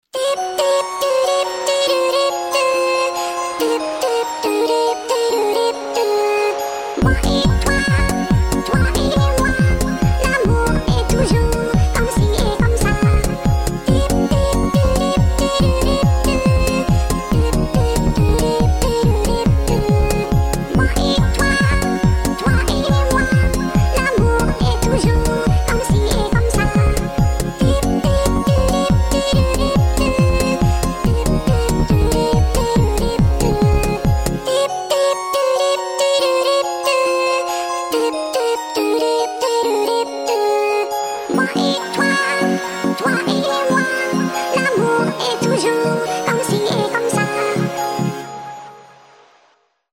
Category: Ringtone